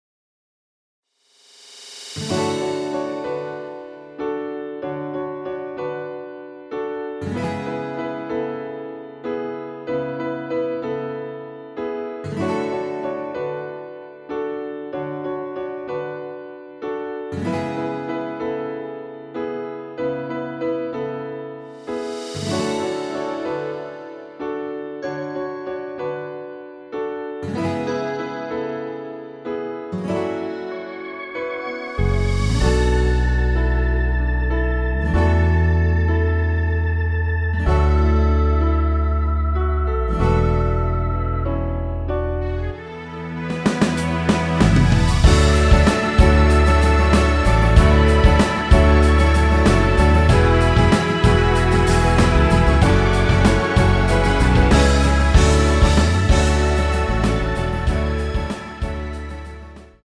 원키에서(-8)내린 MR입니다.
대부분의 남성분이 부르실수 있는 키로 제작되었습니다.(미리듣기 참조)
앞부분30초, 뒷부분30초씩 편집해서 올려 드리고 있습니다.
중간에 음이 끈어지고 다시 나오는 이유는